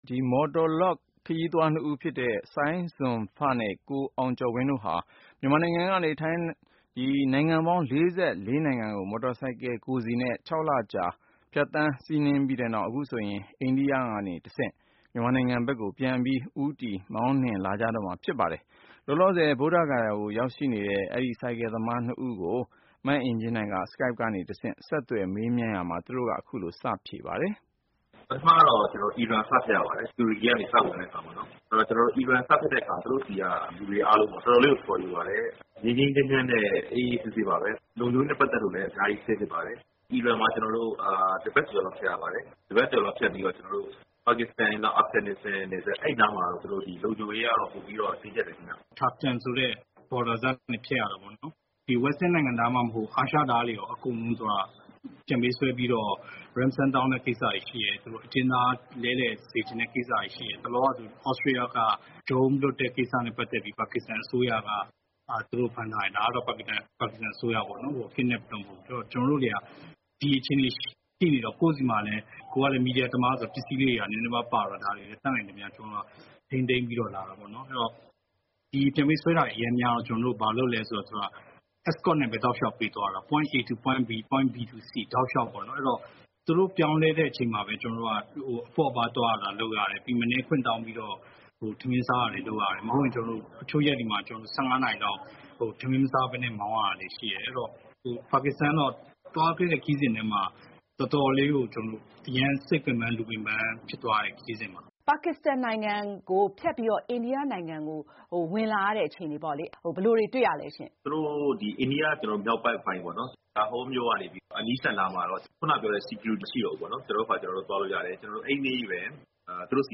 ဆိုင်ကယ်စီး ကမ္ဘာပတ် မြန်မာလူငယ် ၂ ဦး အိမ်အပြန် ခရီးတထောက် Skype နဲ့ ဆက်သွယ်မေးမြန်းချက်